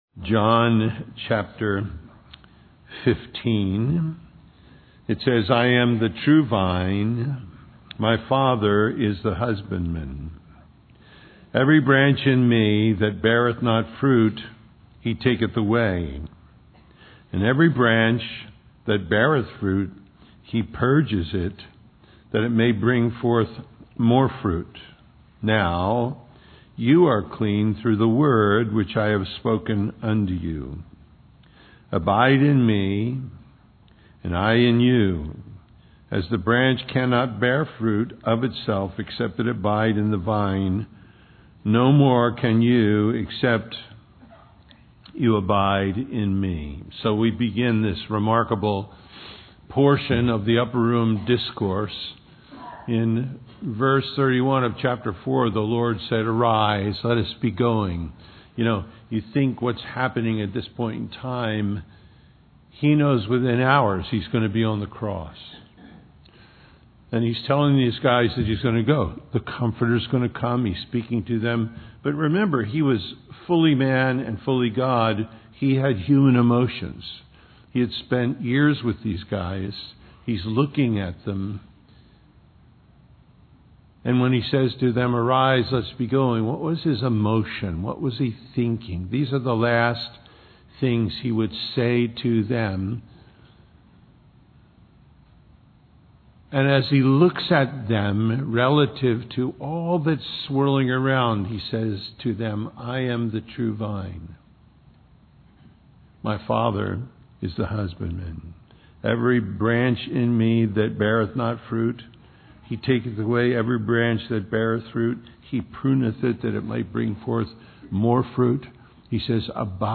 John 15:1-15:4 Abiding Life Listen Download Original Teaching Email Feedback 15 I am the true vine, and my Father is the husbandman.